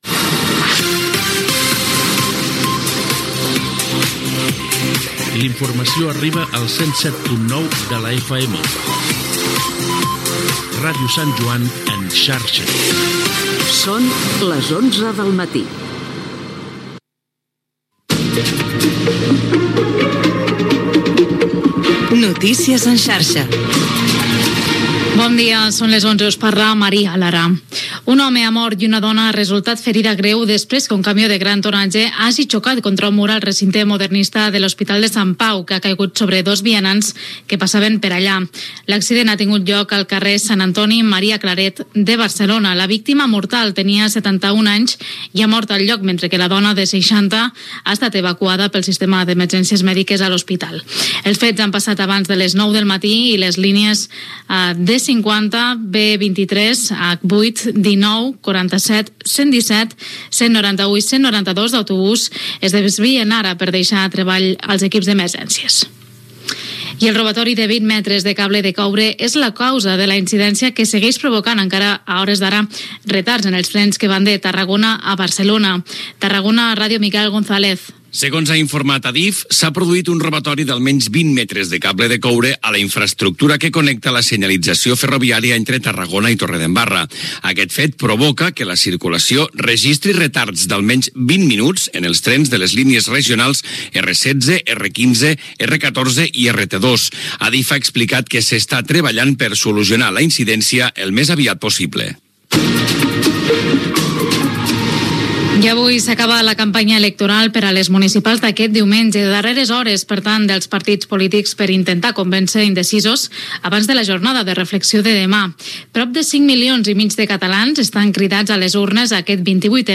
Indicatiu de l'emissora, hora, careta de "Totes les músiques del món", tema musical, indicatiu, presentació d'un tema musical.
Informatiu
Musical
FM